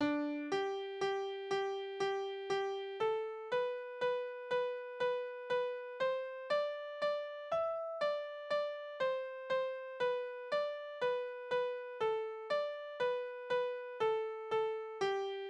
Kindertänze: Hausstand des Bauern
Tonart: G-Dur
Besetzung: vokal